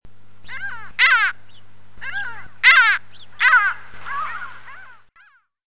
Mouette mélanocéphale
Larus melanocephalus